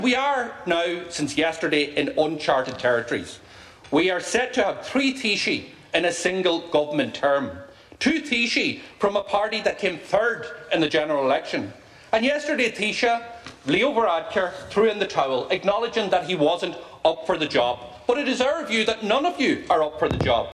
Finance Spokesperson and Donegal TD Pearse Doherty told Tánaiste Micheal Martin in the Dáil it’s now time for an election…………